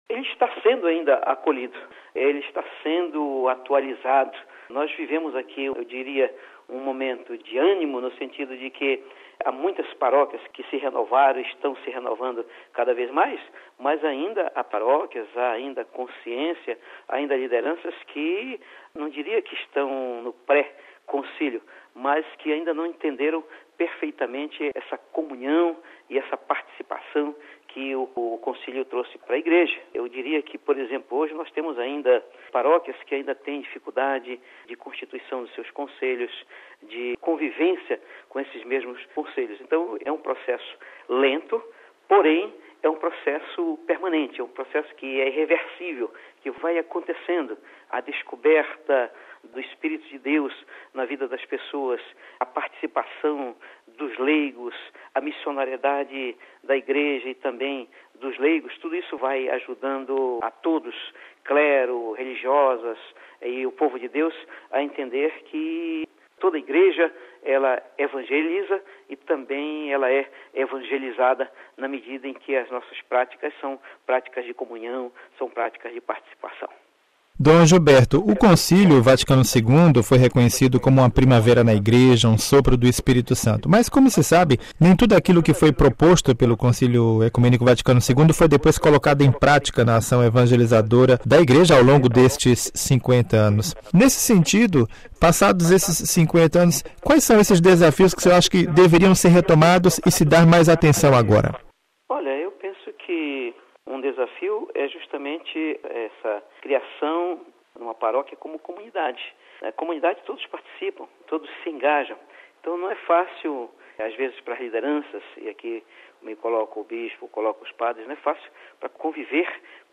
Cidade do Vaticano (RV) - Amigo ouvinte, o quadro "Nova Evangelização e Concílio Vaticano II" de hoje continua com a participação do bispo de Imperatriz do Maranhão, Dom Gilberto Pastana de Oliveira.